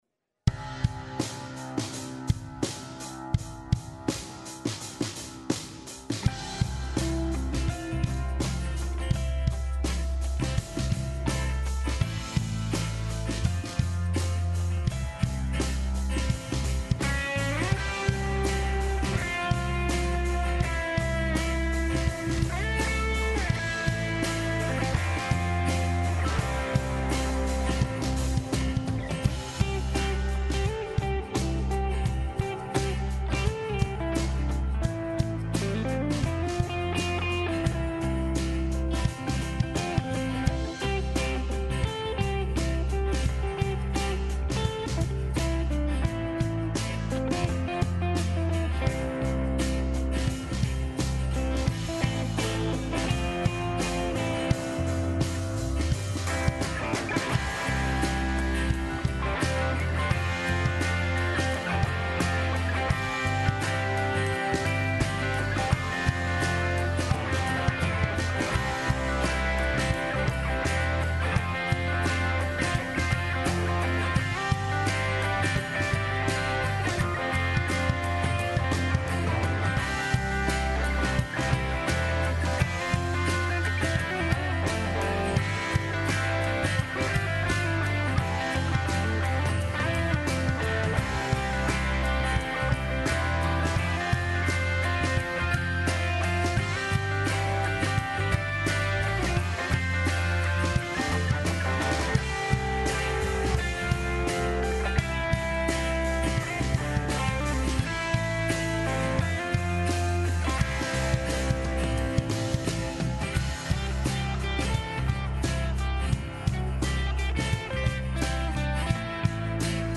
Westgate Chapel Sermons